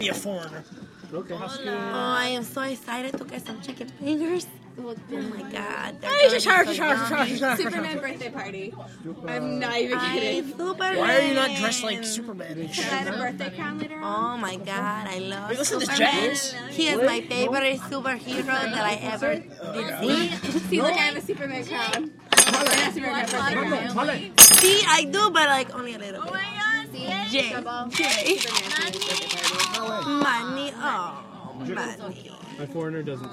Field Recording #3
Noises: People doing accents, music in the background, silverware dropping, ice rattling in glass, misc. other sounds